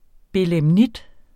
Udtale [ belεmˈnid ]